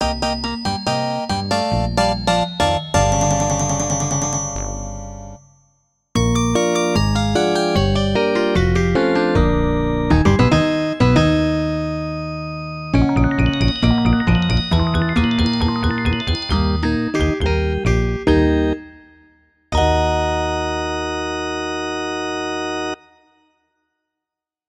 Converted from .mid to .ogg